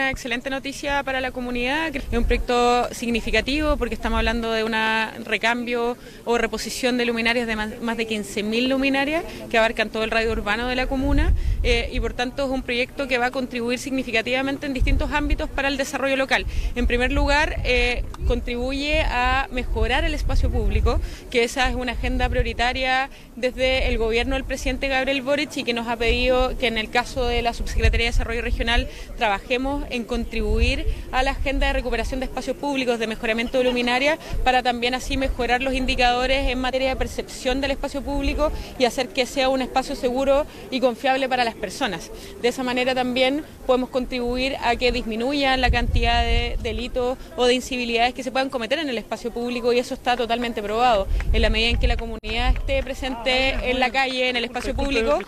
Este martes, se dio inicio al recambio de 15.872 luminarias en el radio urbano de Osorno, en un acto celebrado en la Plaza España de Rahue Alto.
La Subsecretaria Francisca Perales destacó que la iniciativa viene a contribuir en la recuperación de espacios públicos, mejorando la sensación de la comunidad gracias a la mejor iluminación del entorno.